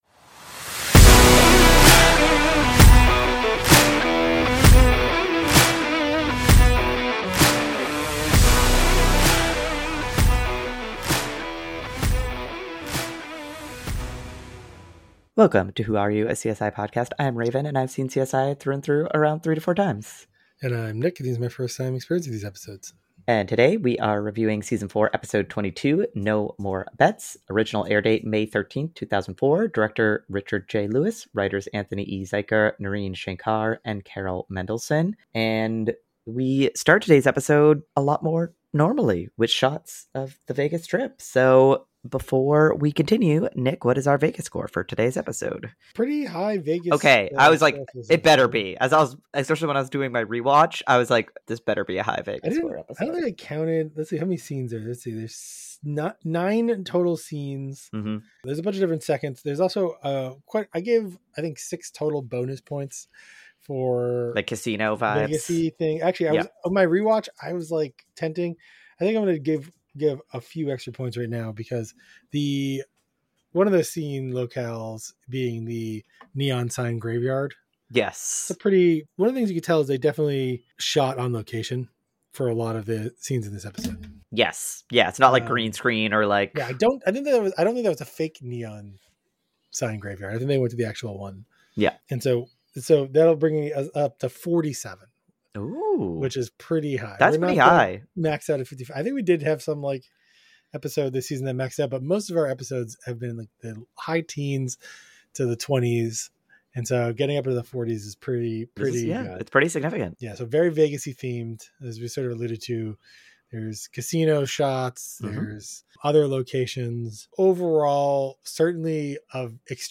hosted by two friends